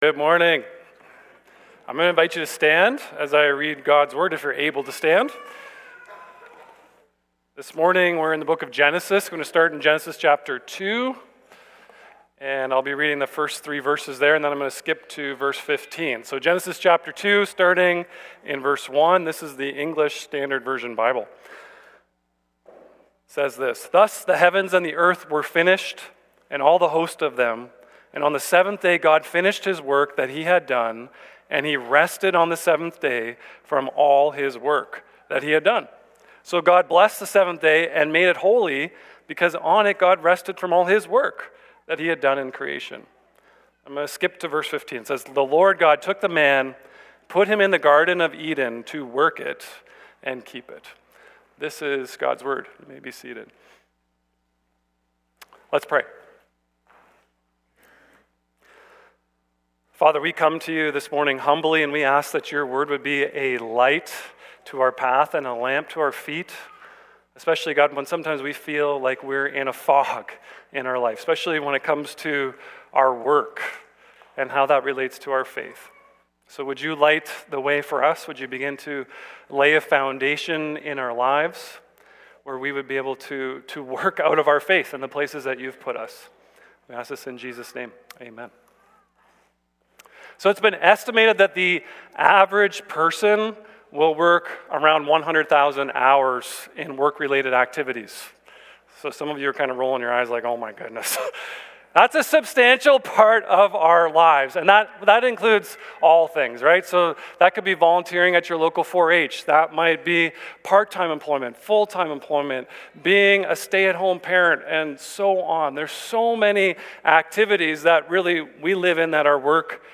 Sermons | Brooks Evangelical Free Church